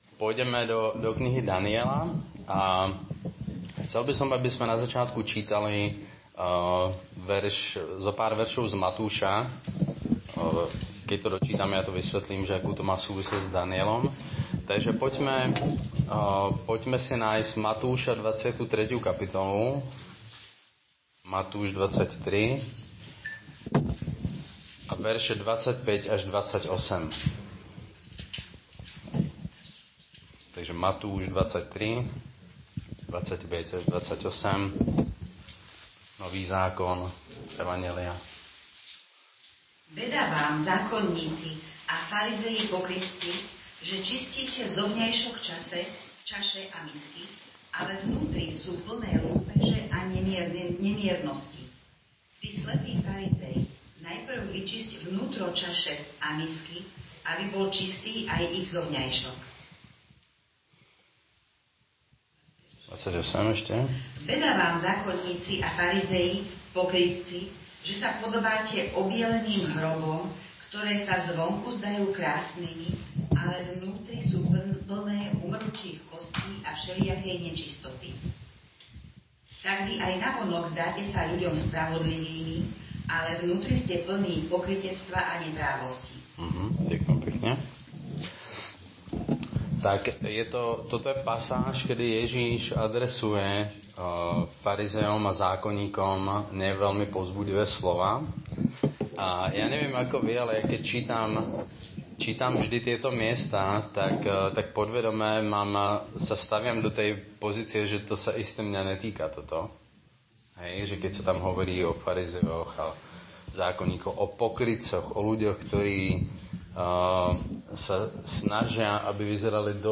Nahrávka kázne Kresťanského centra Nový začiatok z 8. apríla 2018